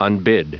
Prononciation du mot unbid en anglais (fichier audio)
Prononciation du mot : unbid